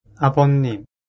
発音と読み方
韓国人講師の音声を繰り返して聞きながら発音と読み方をしっかり覚えましょう。
아버님 [アボニム]